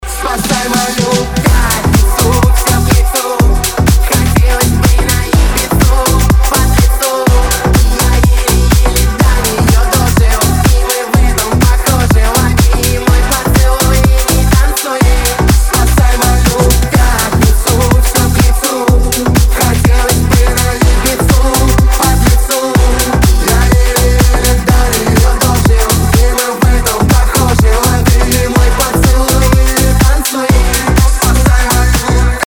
• Качество: 320, Stereo
dance